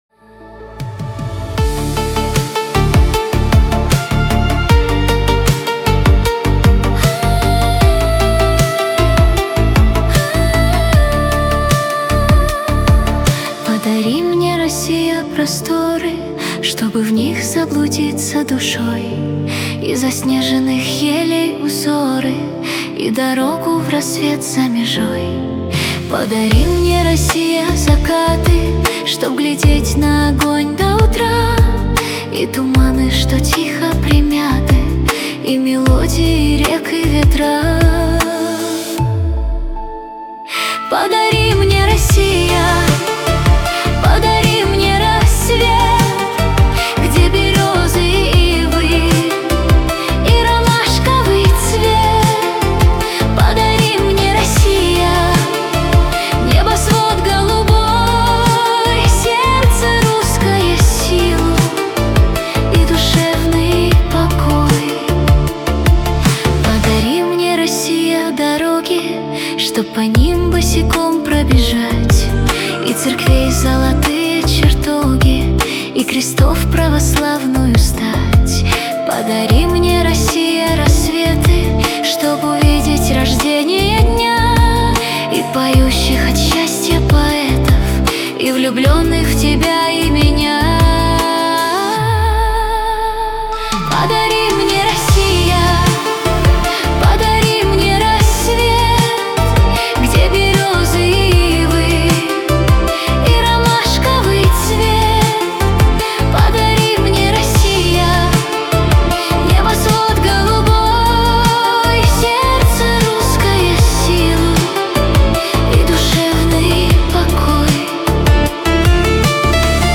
• Категория: Детские песни
теги: Россия, минус, лирическая